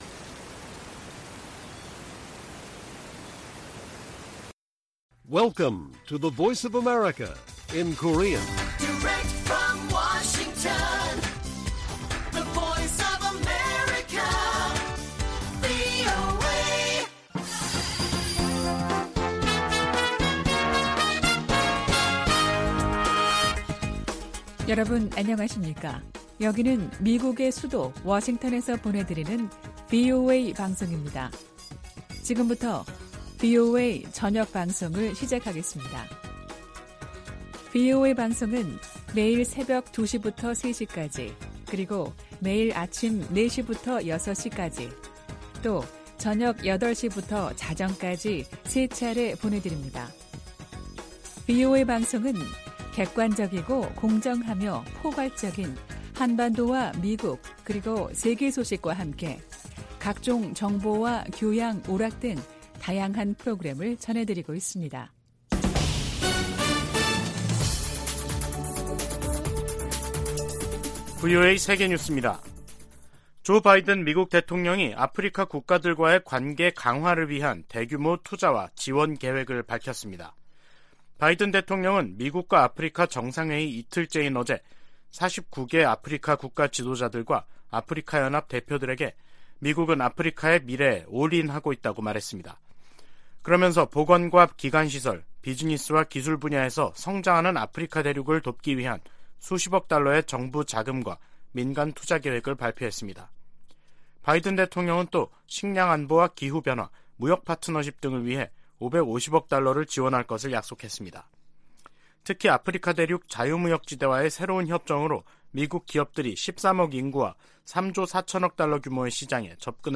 VOA 한국어 간판 뉴스 프로그램 '뉴스 투데이', 2022년 12월 15일 1부 방송입니다. 북한 김정은 정권이 주민을 착취해 무기 프로그램을 증강하고 있다고 국무부가 지적했습니다. 미국 의회가 새 국방수권법 합의안에서 핵전력을 현대화하고 미사일 방어를 강화하기 위한 예산을 계속 지원하기로 했습니다.